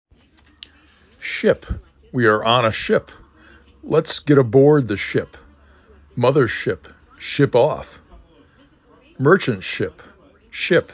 S i p
sh ip